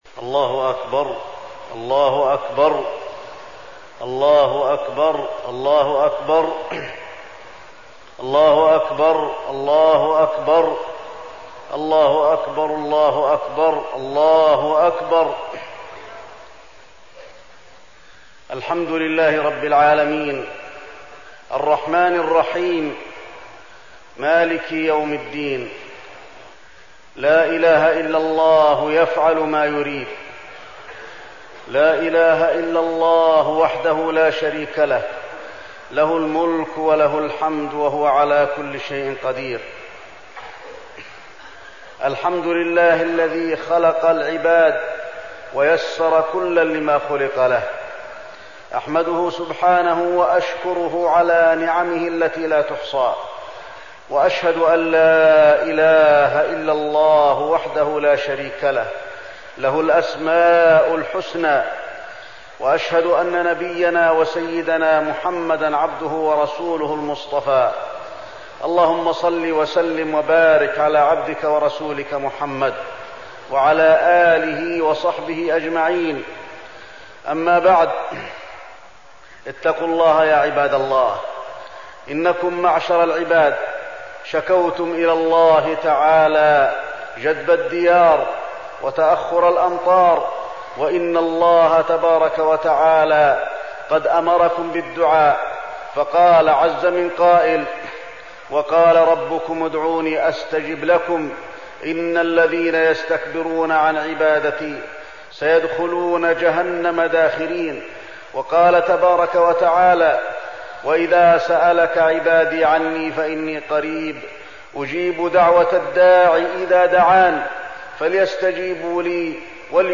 خطبة الاستسقاء - المدينة- الشيخ علي الحذيفي - الموقع الرسمي لرئاسة الشؤون الدينية بالمسجد النبوي والمسجد الحرام
تاريخ النشر ٢٤ رجب ١٤١٧ هـ المكان: المسجد النبوي الشيخ: فضيلة الشيخ د. علي بن عبدالرحمن الحذيفي فضيلة الشيخ د. علي بن عبدالرحمن الحذيفي خطبة الاستسقاء - المدينة- الشيخ علي الحذيفي